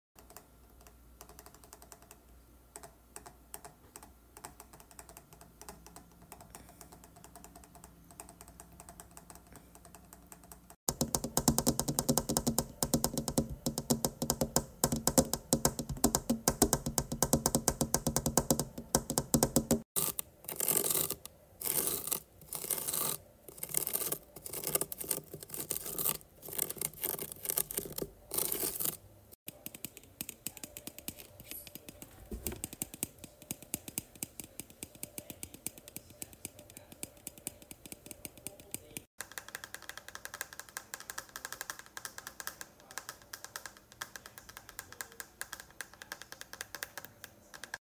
ASMAR tapping and scratching 😁🫶🏼